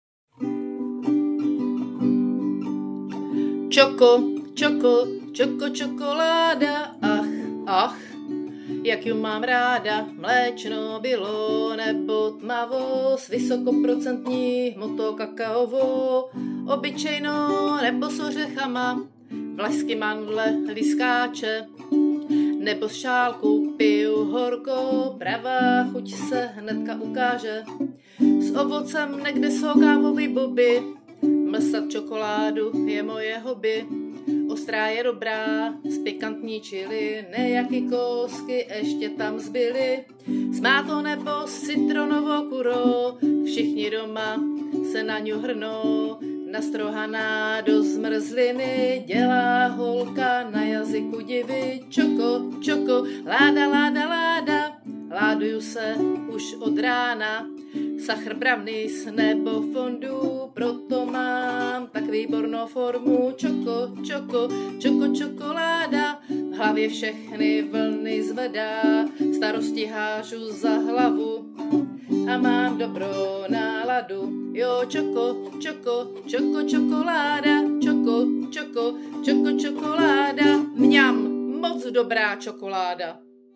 Anotace: Zpěv po našem